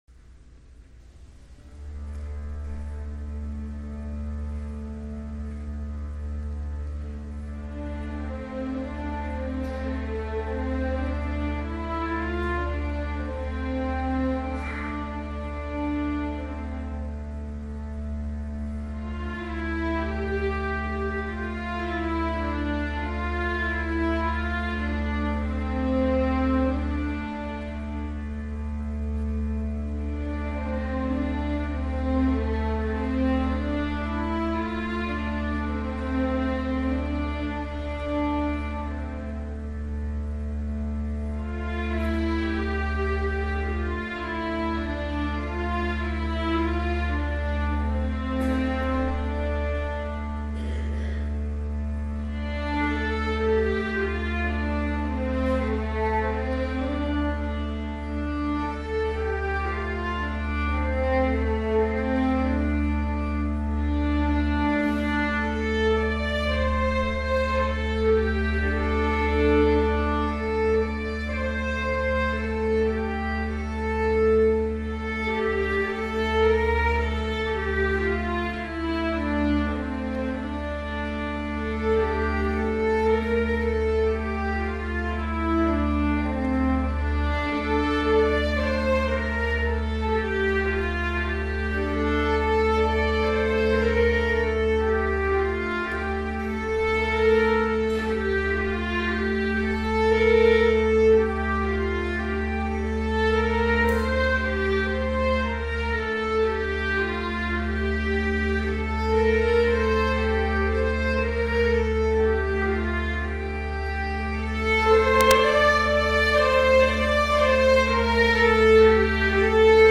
Instrumentation: String Orchestra
Sacred Music